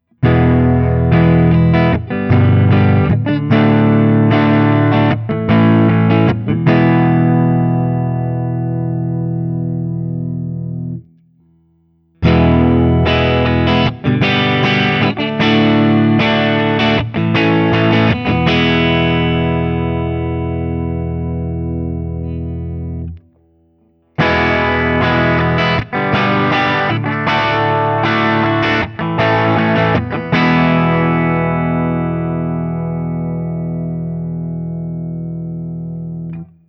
Open Chords #2
As usual, for these recordings I used my normal Axe-FX III  setup through the QSC K12 speaker recorded direct into my Mac Pro using Audacity.
For each recording I cycle through the neck pickup, both pickups, and finally the bridge pickup.
Guild-Nightbird-DX-ODS100-Open2.wav